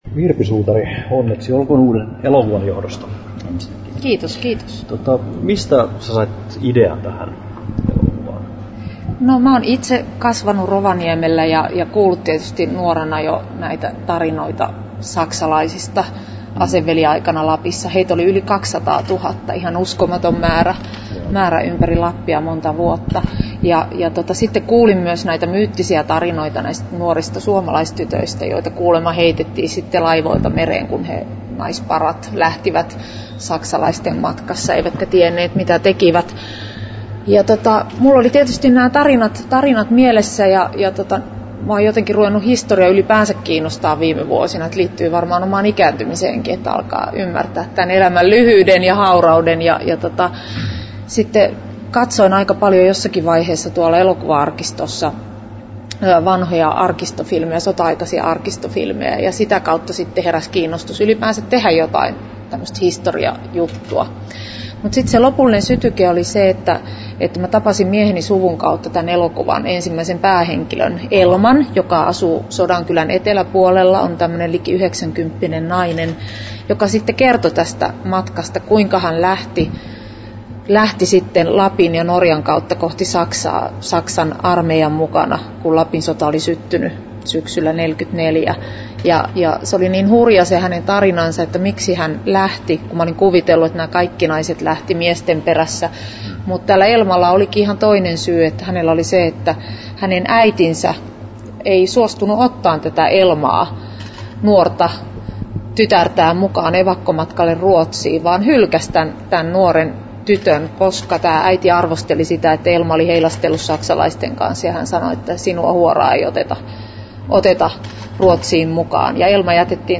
18'54"" Tallennettu: 2.3.2010, Turku Toimittaja